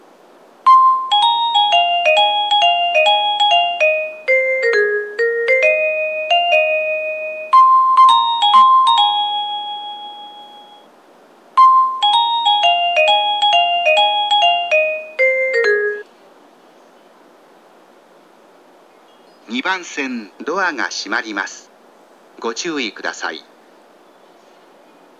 矢野口駅　Yanokuchi Station ◆スピーカー：小VOSS
2番線発車メロディー